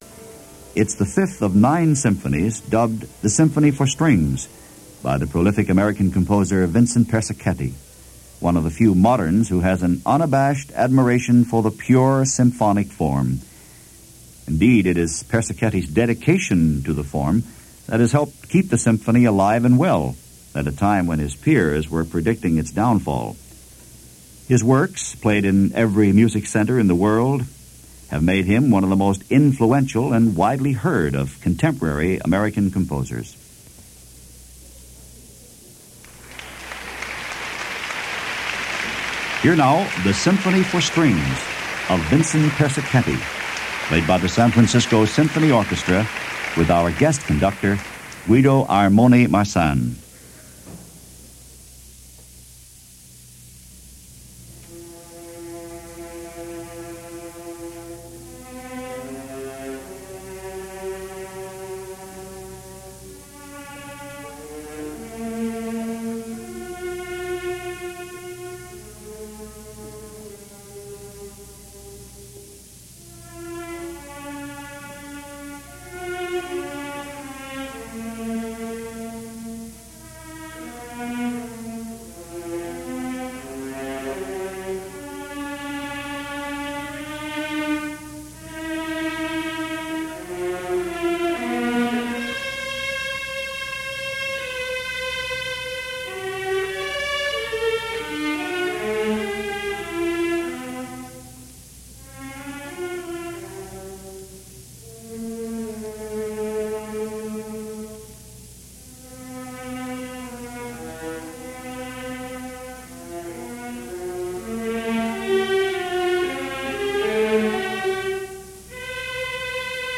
A rather short concert this week – short, in the sense that only two pieces survived and the third piece (the Mendelssohn “Scottish” Symphony) was sadly incomplete and badly damaged.
The San Francisco Symphony, in this concert from the 1978-1979 season features guest Conductor Guido Ajmone Marsan and the legendary Rudolf Firkusny in Beethoven’s 4th piano Concerto. The concert begins with Vincent Persichetti’s Symphony For Strings (Symphony Number 5).